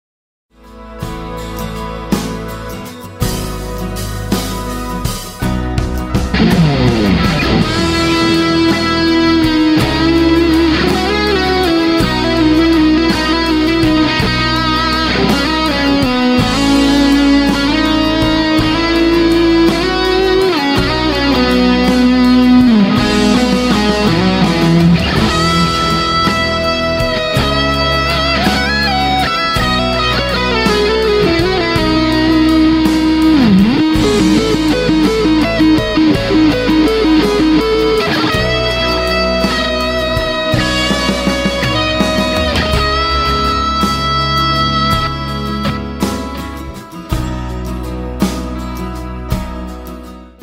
ZOOM G5n - GD SOULFUL LEAD (soundtest)